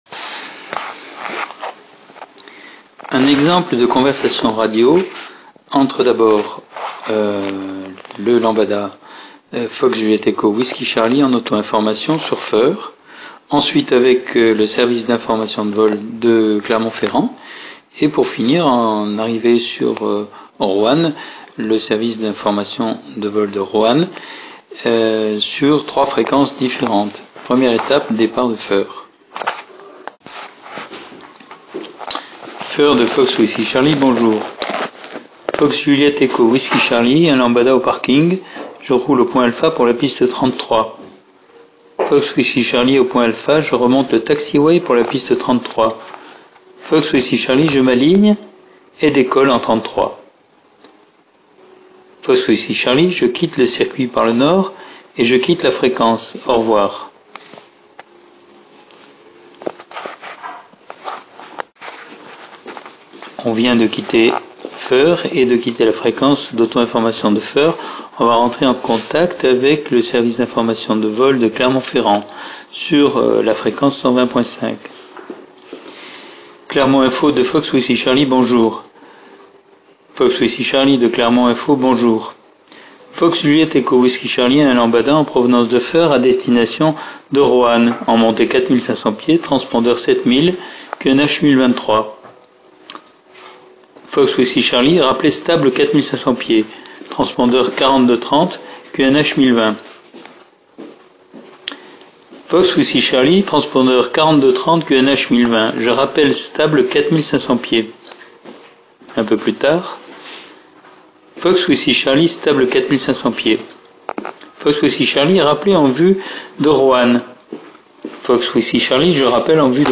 Un exemple de phraséologie  mp3 sur un trajet départ en Auto information , relayé par une prise de contact avec SIV et enfin un aérodrome avec Information de vol .
phraseo_Feurs_roanne.mp3